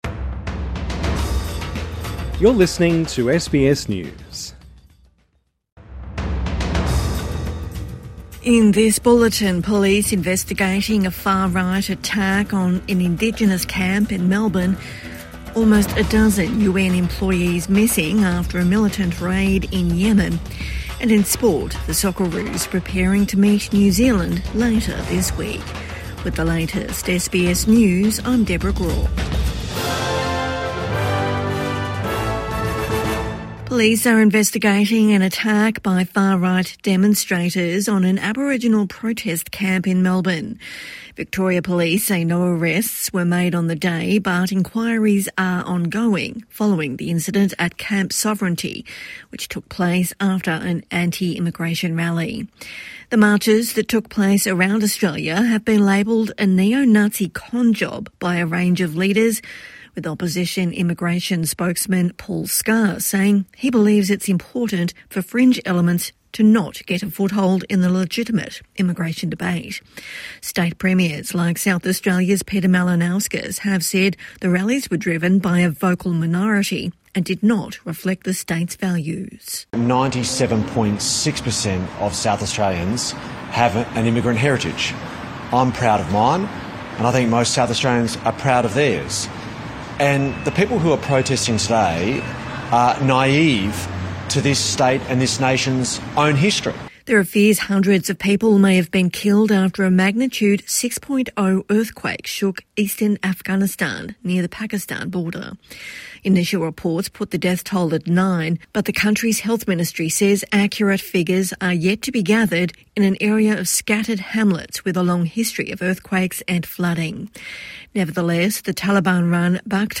Police probe far right attack on Indigenous camp in Melbourne| Evening News Bulletin 1 Sep 2025